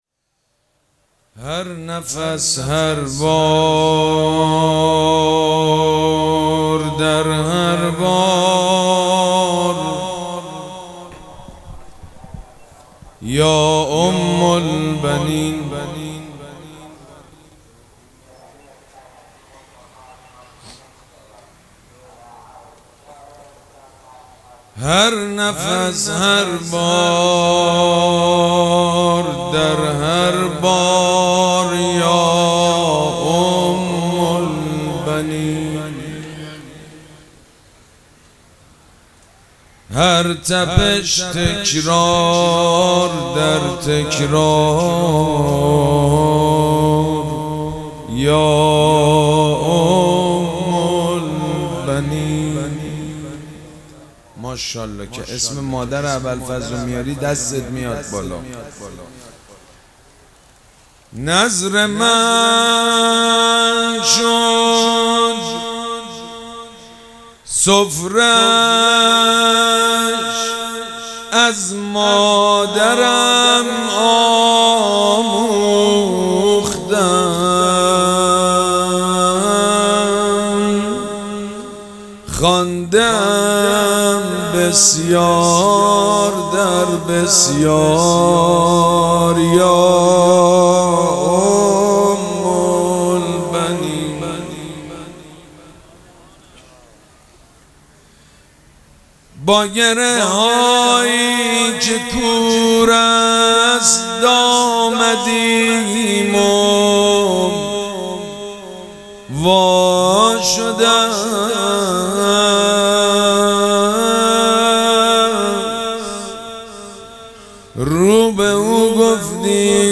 شب چهارم مراسم عزاداری اربعین حسینی ۱۴۴۷
روضه
حاج سید مجید بنی فاطمه